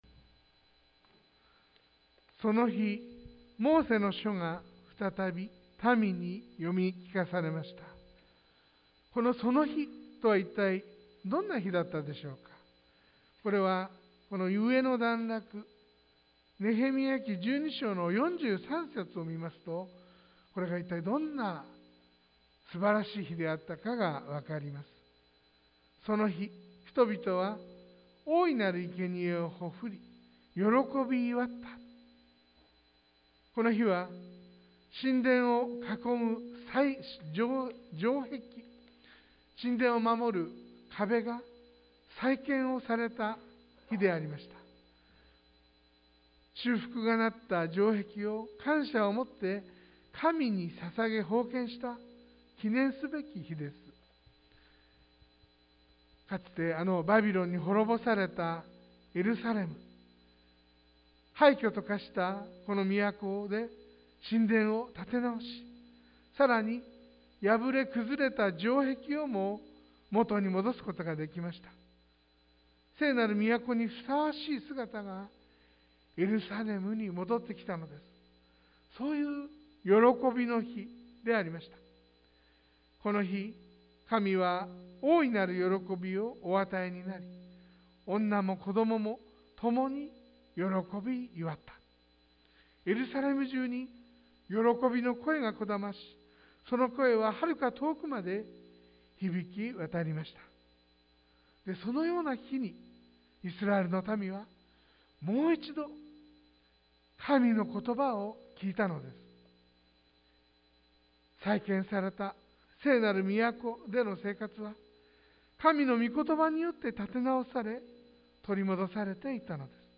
sermon-2021-03-07